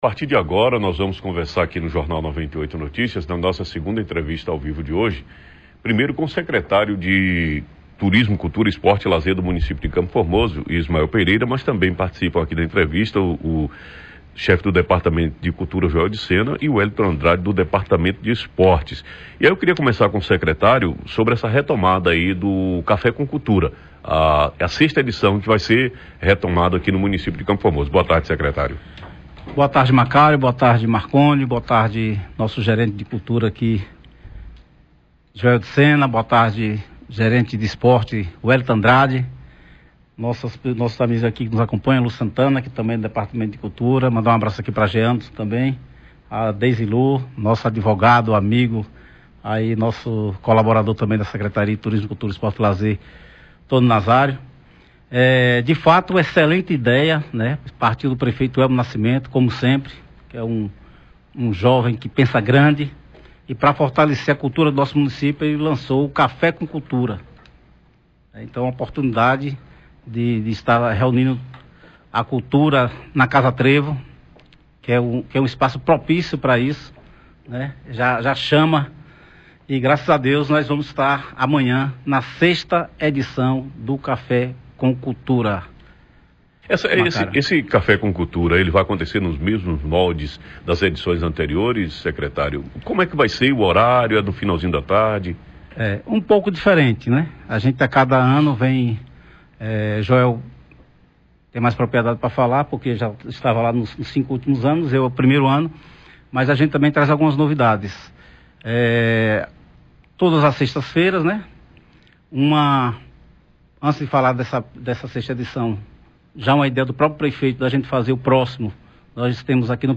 Secretário de cultura de CFormoso, Ismael Pereira – 6ª edição do Café com Cultura
ENTREVISTA-SECRETARIO.mp3